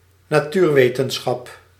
Ääntäminen
IPA: /nɑ.ˈtyːr.ʋeː.tə(n).sxɑp/